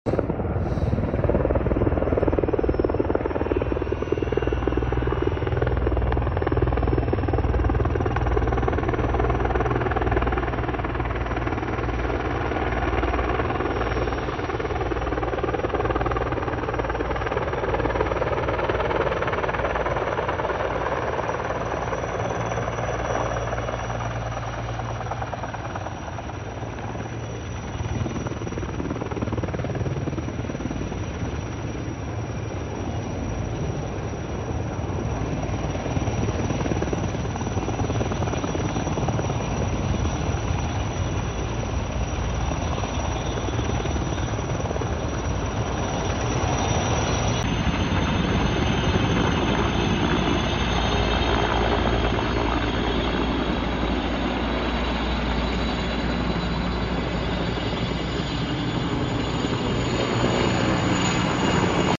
The SkyCrane is one of the mightiest Fire-Fighting Helicopters in the World. These pilots are dropping thousands of gallons of water on top of the Line Fire in California, to save thousands of people and homes from the flames.